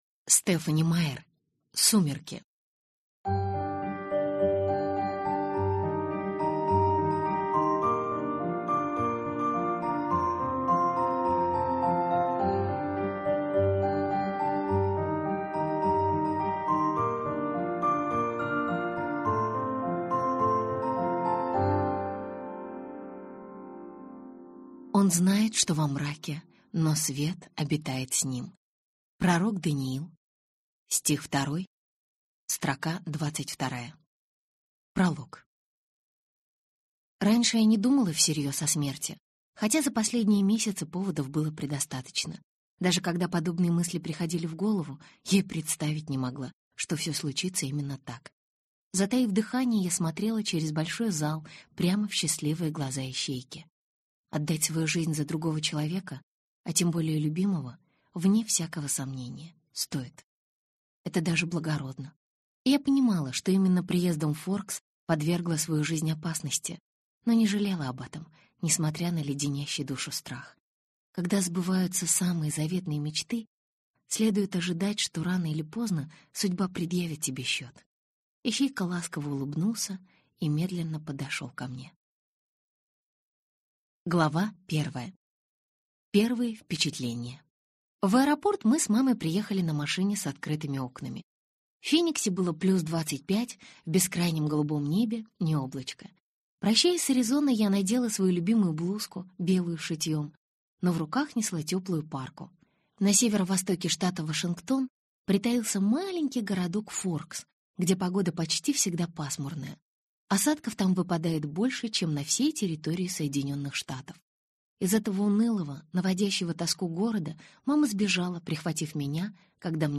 Аудиокнига Сумерки | Библиотека аудиокниг